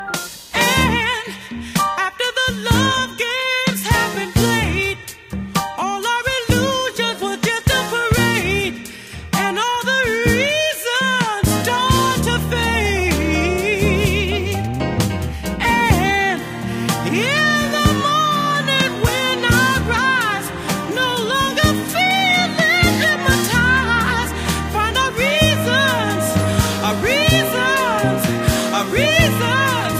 This is a sound sample from a commercial recording.
It is of a lower quality than the original recording.